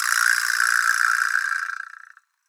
vibraslap-small02.wav